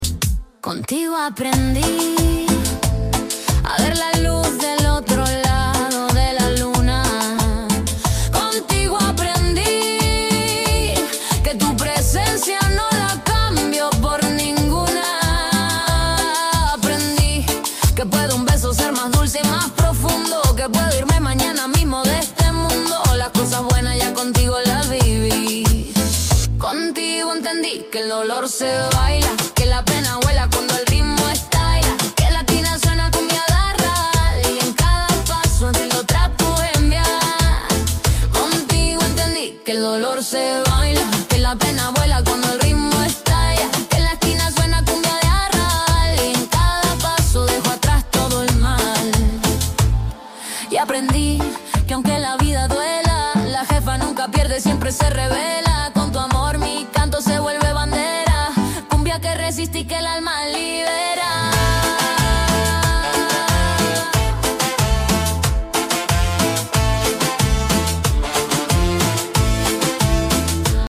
Version cumbia Argentina porteña
Cumbia porteña Argentina